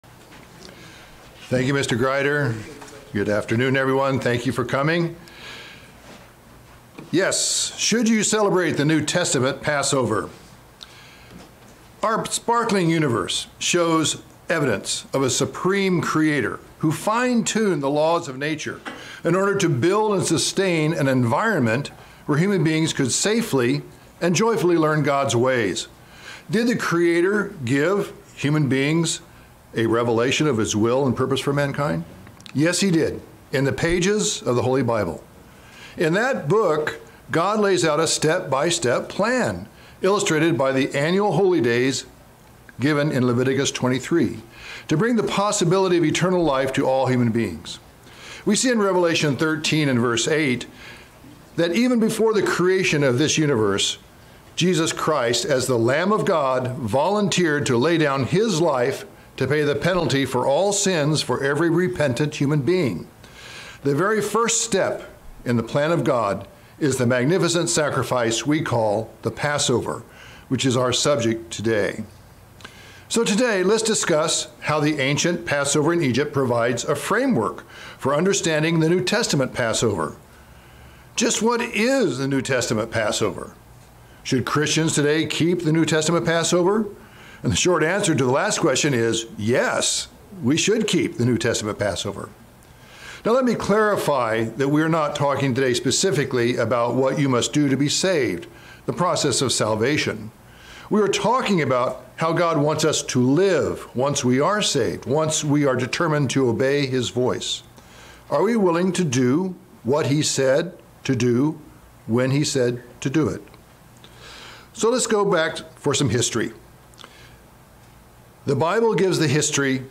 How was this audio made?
Given in Northern Virginia